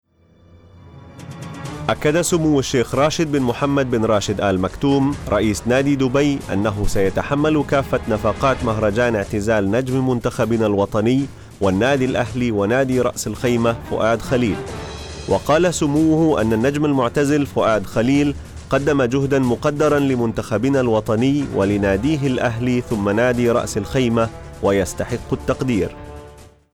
Sprecher arabisch.
Sprechprobe: Werbung (Muttersprache):
arabian voice over artist